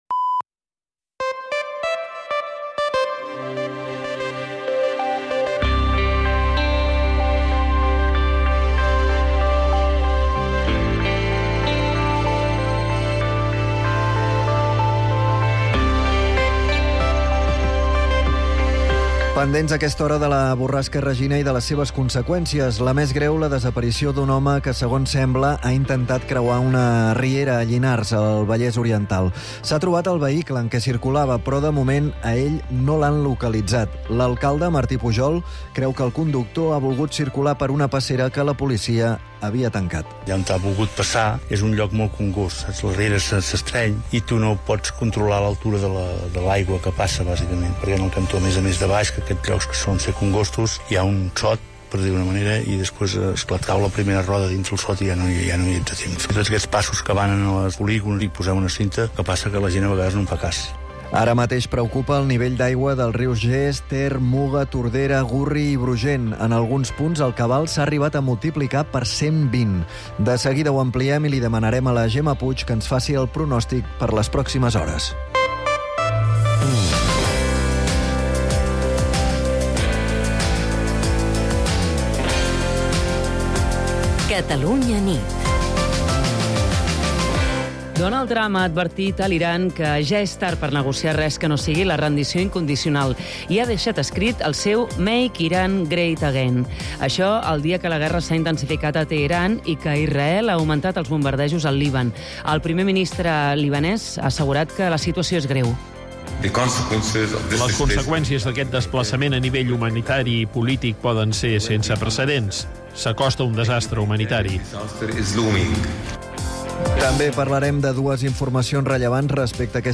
l'informatiu nocturn de Catalunya Ràdio